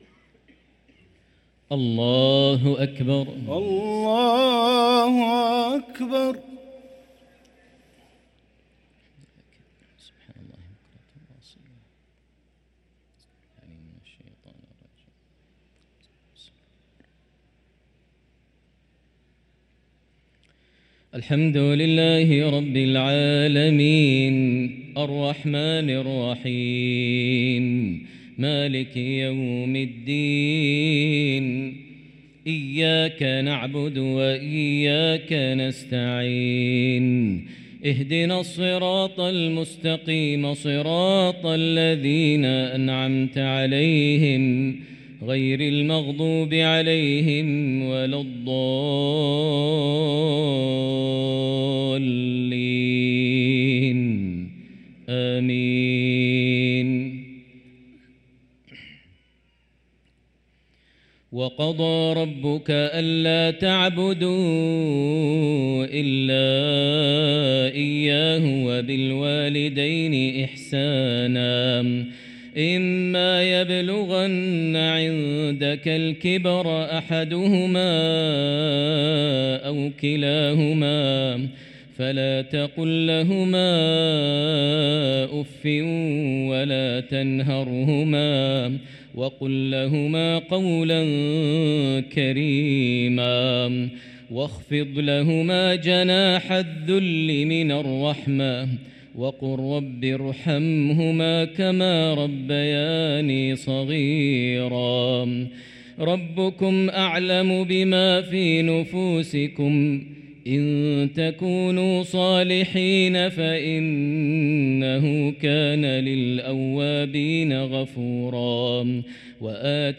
صلاة العشاء للقارئ ماهر المعيقلي 6 ربيع الآخر 1445 هـ
تِلَاوَات الْحَرَمَيْن .